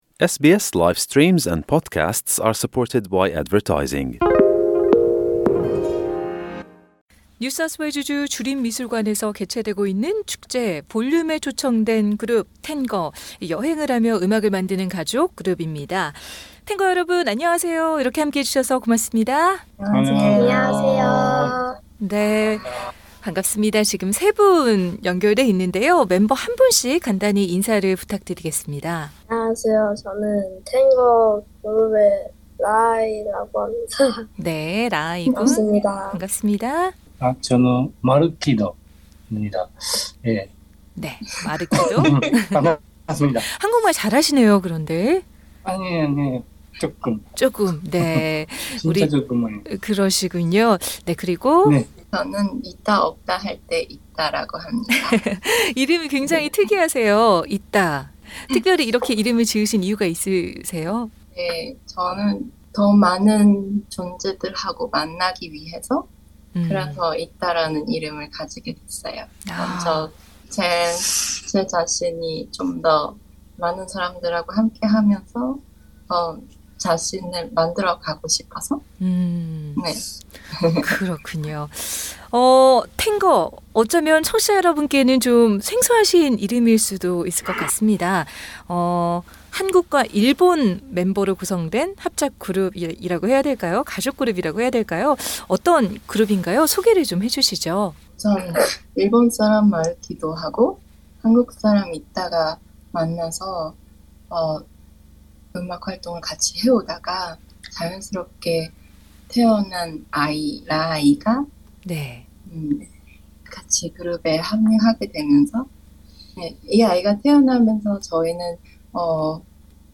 인터뷰: 세계를 떠돌며 음악으로 경계를 무너뜨리는 가족 밴드 ‘텐거TENGGER’